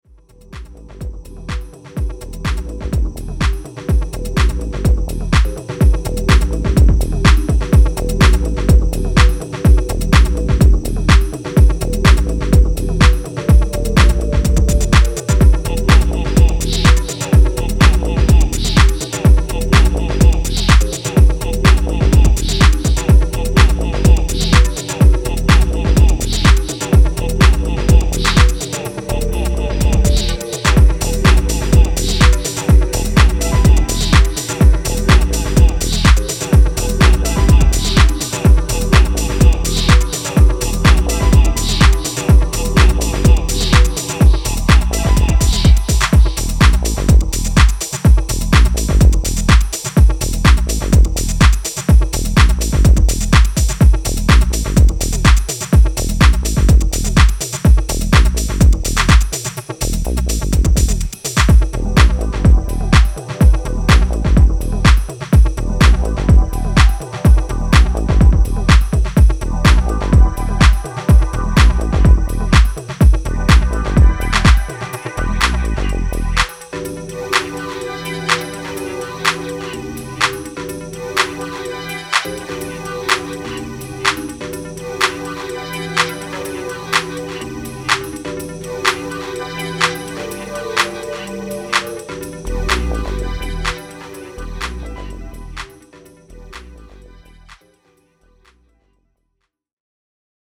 ゆらめくダビーなベース&エフェクトの中でピアノリフが反響するディープ・ハウス